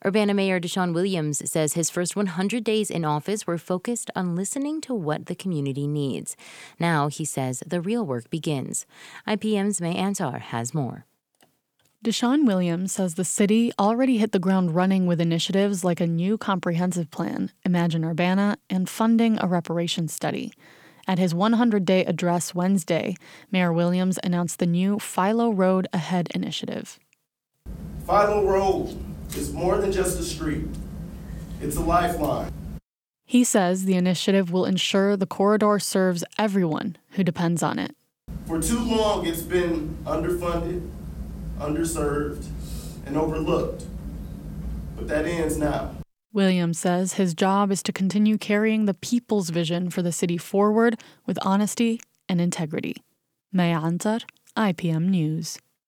URBANA – At his 100-Day address on Wednesday, Urbana Mayor DeShawn Williams said he will continue to carry the people’s vision for the city forward with honesty and integrity.